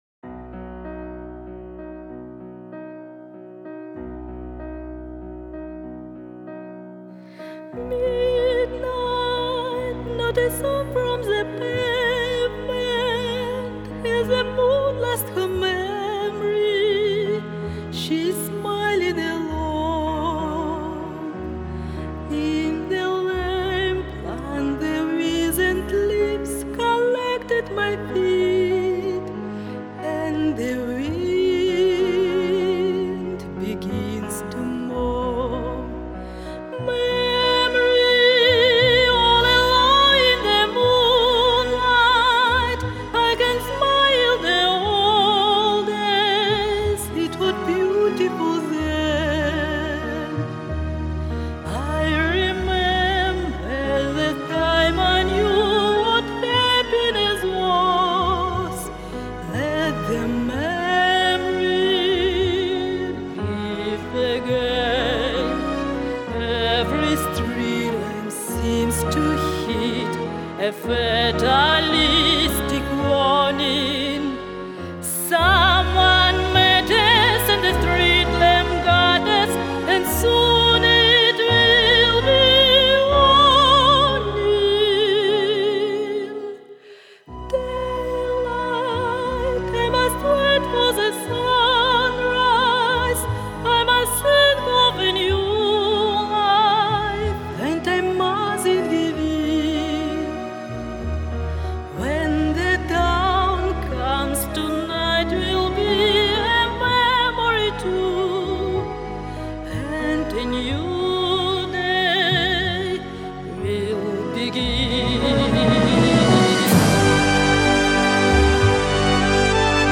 ЖИВОЙ ЗВУК